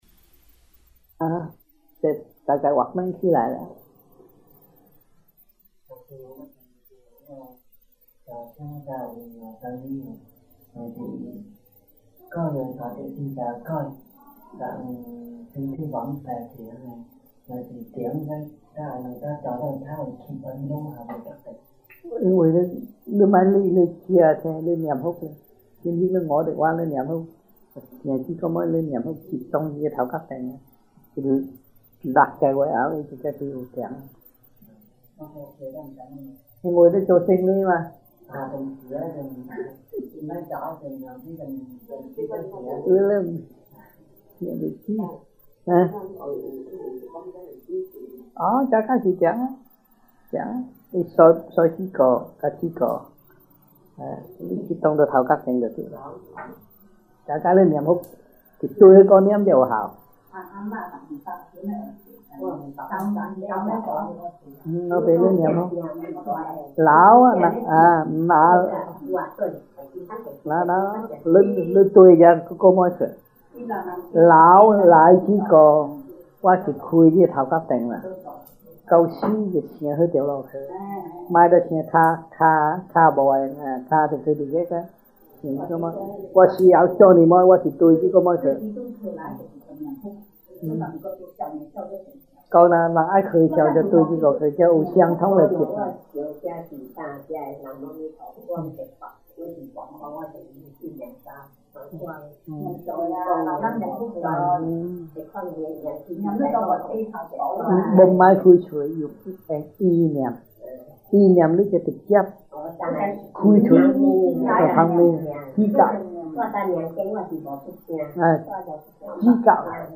Q&A in Chinese-1981 (中文問答題)